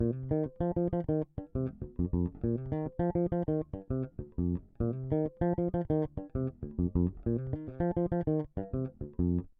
低音音乐放克
描述：F bpm 120 你好，要知道我所有的贝司，都是用原始声音录制的，没有经过编辑直接从贝司到前置放大器（老板RC 505）。
Tag: 100 bpm Funk Loops Bass Guitar Loops 1.75 MB wav Key : F